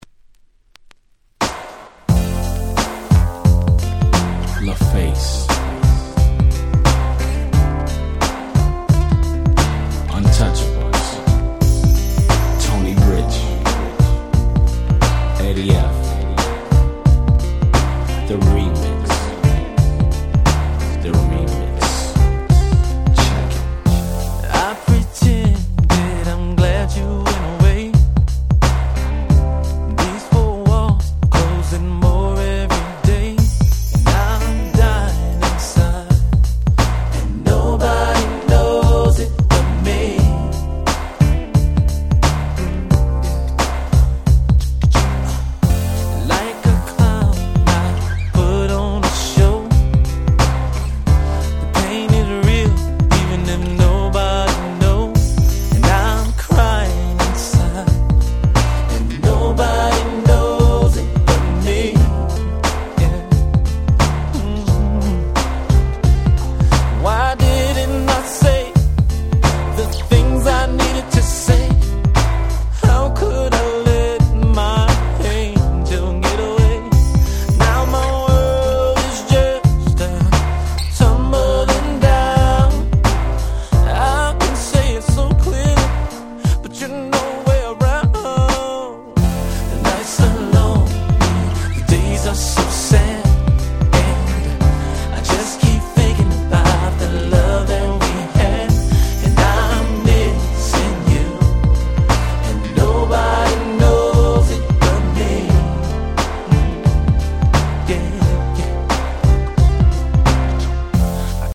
95' Super Hit Slow/Mid R&B !!
スロウジャム バラード 90's